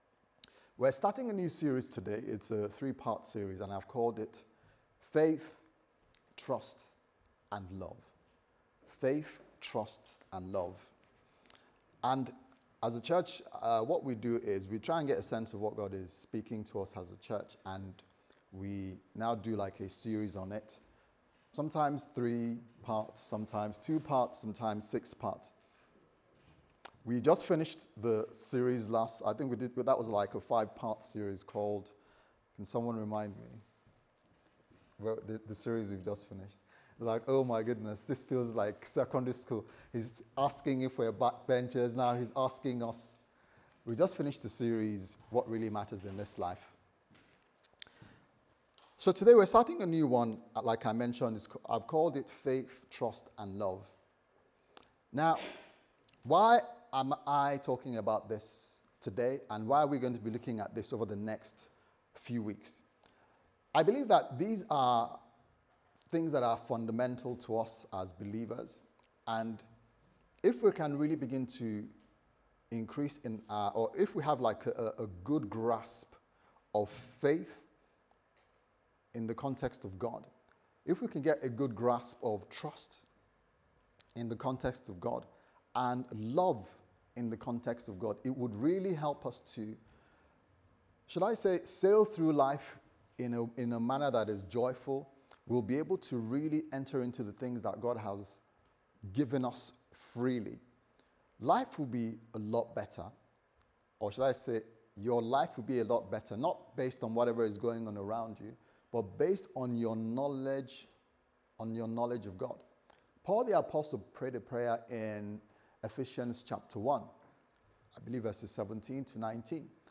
Faith Trust And Love Service Type: Sunday Service Sermon « What Really Matters In This Life